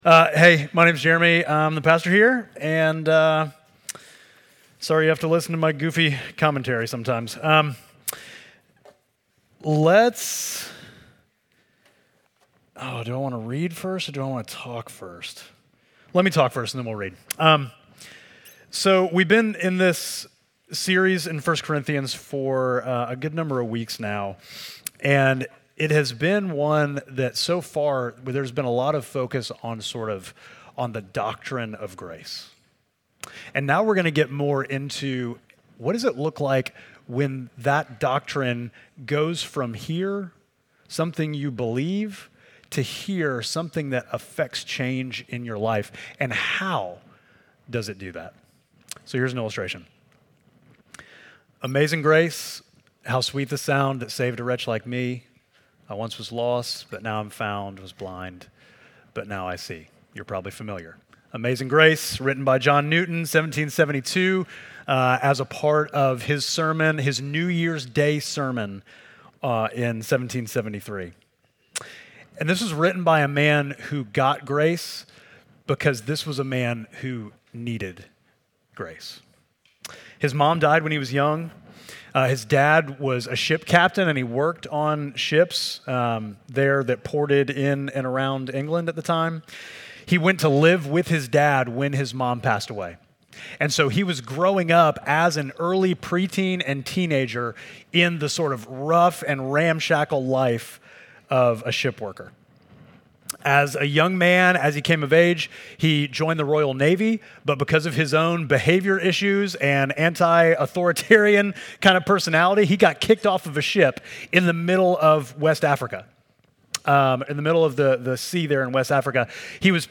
Midtown Fellowship Crieve Hall Sermons Was Blind But Now I See Feb 16 2025 | 00:45:28 1x 00:00 / 00:45:28 Subscribe Share Apple Podcasts Spotify Overcast RSS Feed Share Link Embed